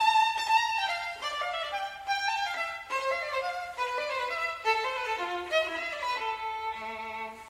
Audición de diferentes sonidos de la familia de cuerda frotada.
Violín